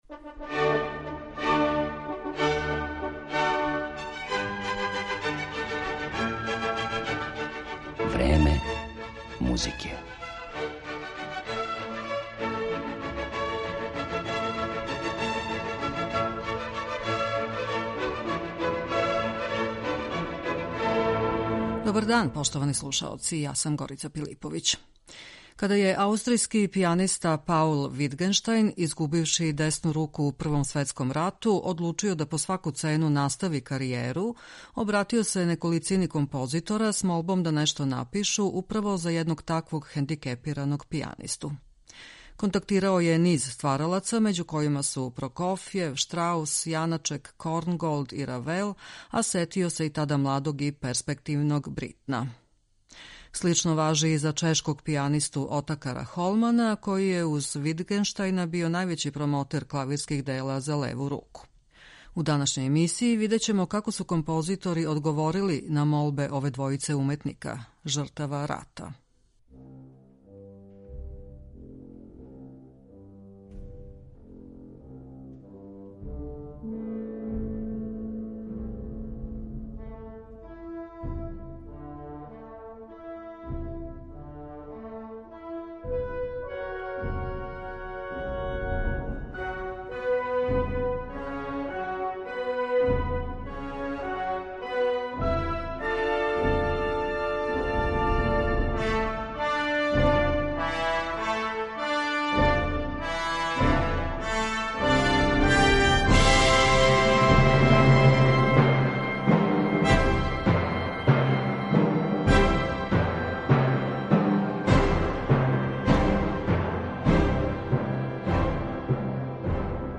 Клавирска музика за леву руку